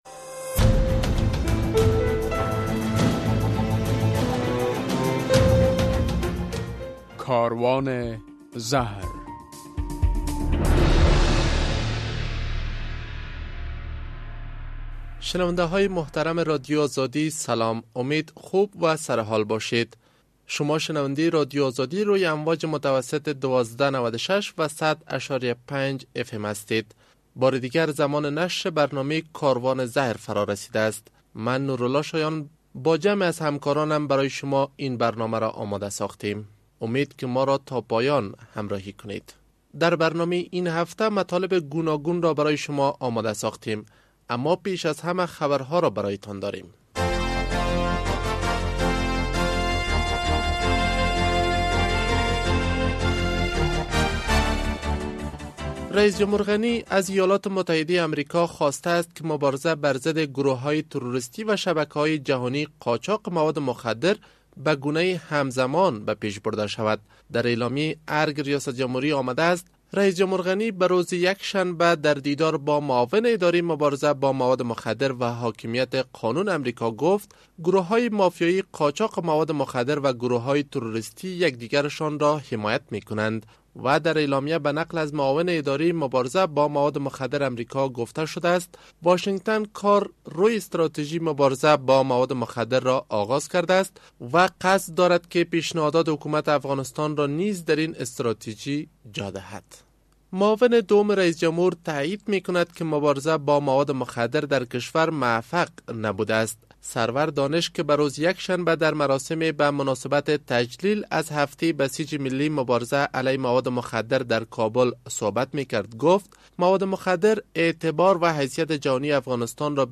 در برنامه این هفته کاروان زهر، در نخست خبرها، بعداً گزارش‌ها، بعد از آن مصاحبه و به تعقیب آن خاطره یک معتاد و ...